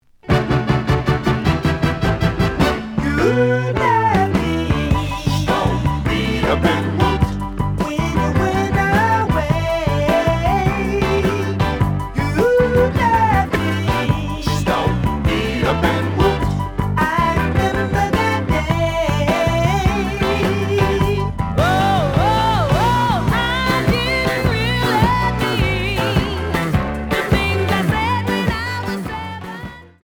(Stereo)
試聴は実際のレコードから録音しています。
●Genre: Funk, 70's Funk